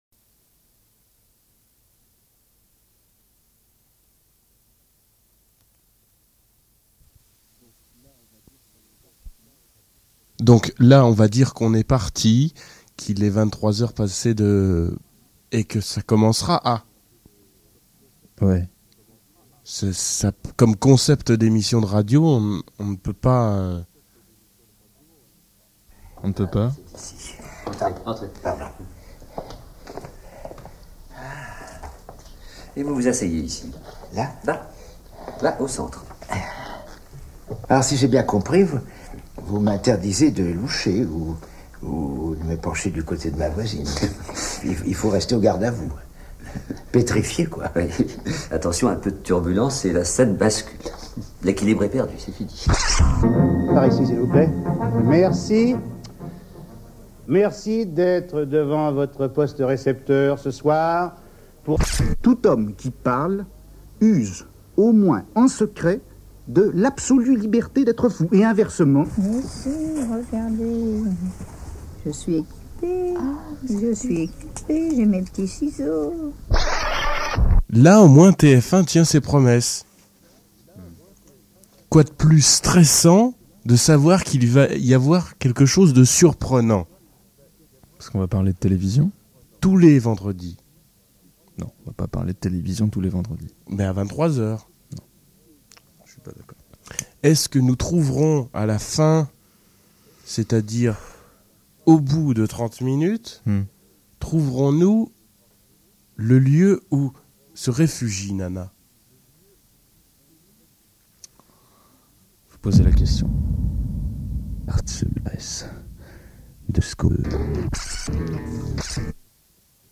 En 6 mots : du work in progress en live. On écoute des sons, et on joue avec le titre de l'émission et son thème.
spécificités : les feuilles sèches qui crissaient